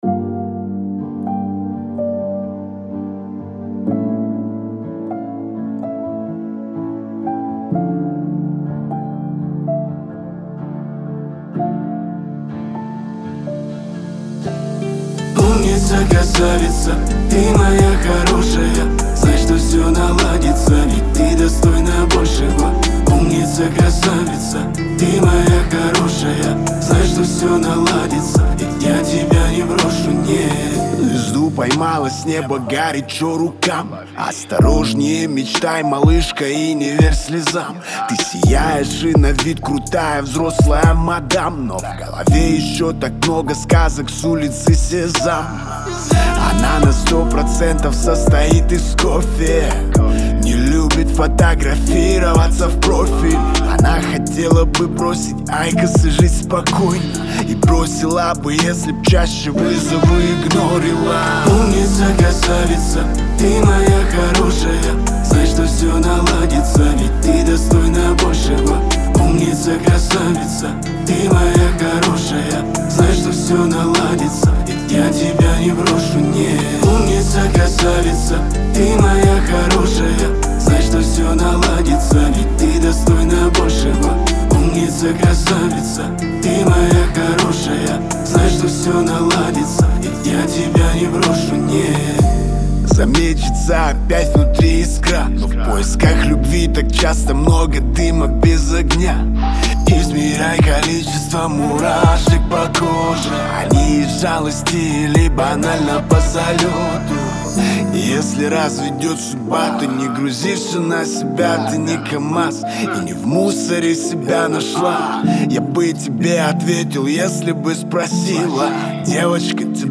это глубокий хип-хоп трек